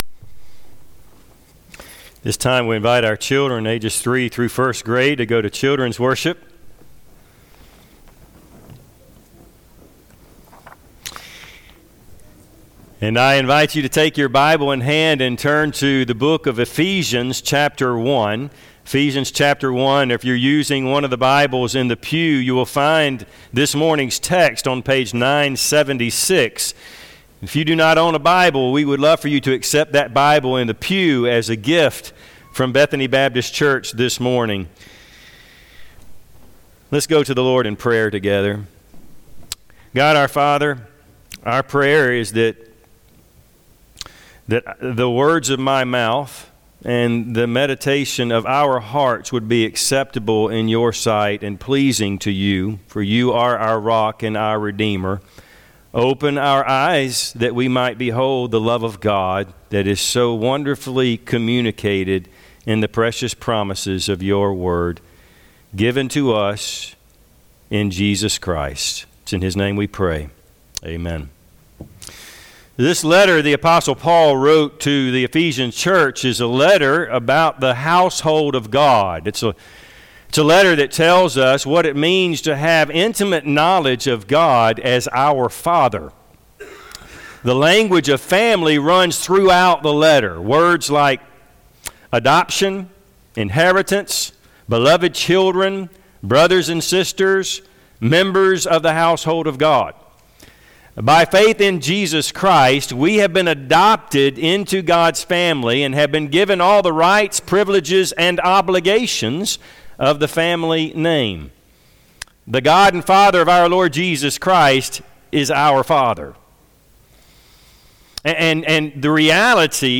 Bible Text: Ephesians 1:11-14 | Preacher